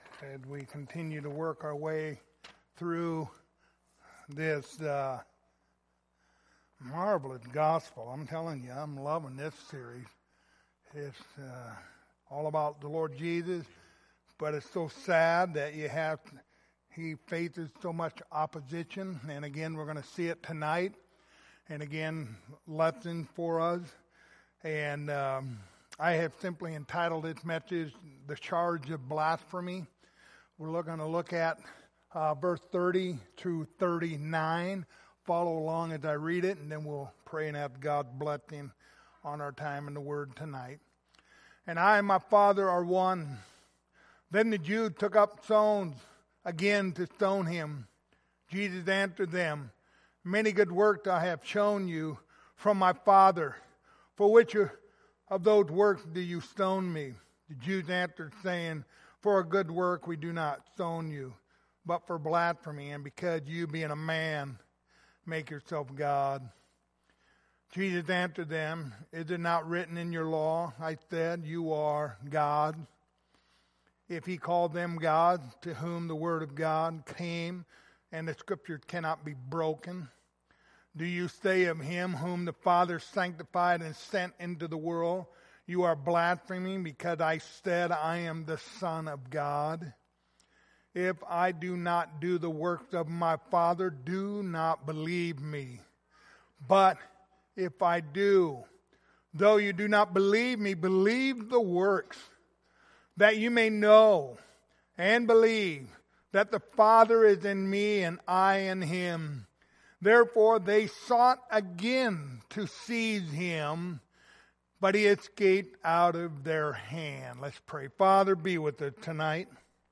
Passage: John 10:31-39 Service Type: Wednesday Evening